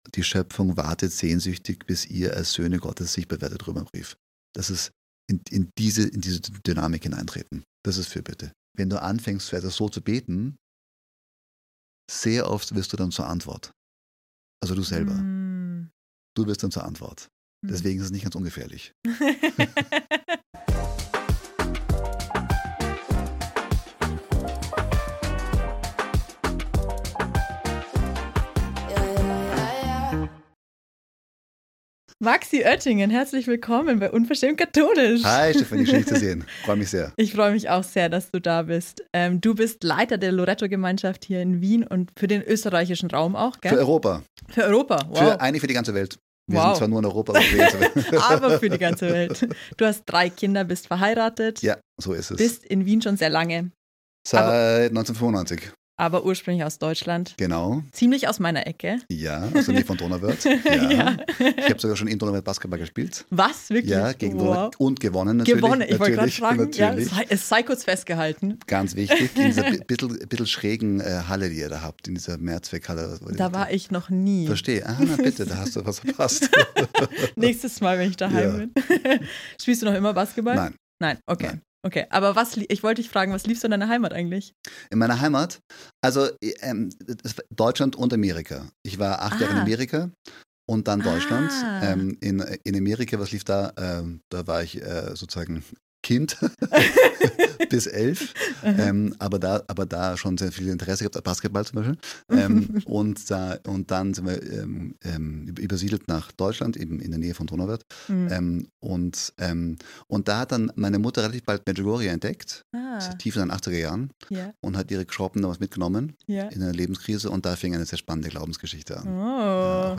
In meinem Gespräch mit ihm reden wir über das Thema Gebet, Glaubensherausforderungen, das Hohelied der Liebe und unserer tiefen Sehnsucht nach Einssein...